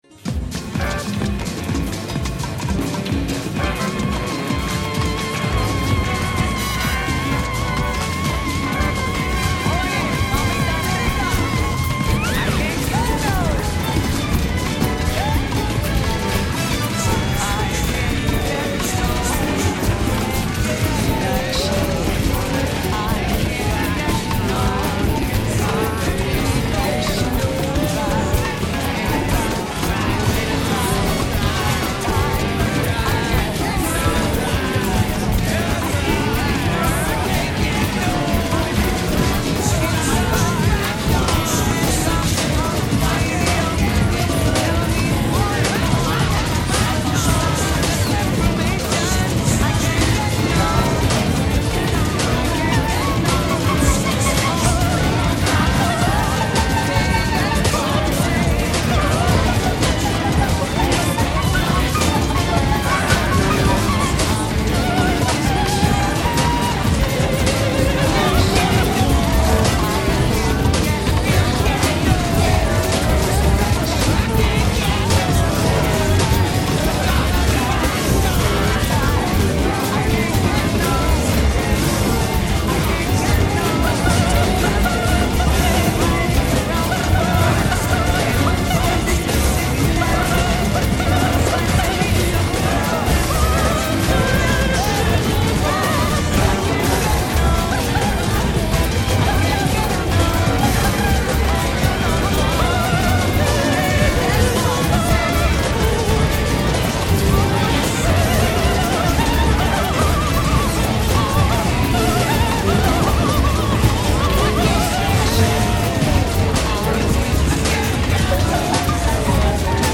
per sovrapposizioni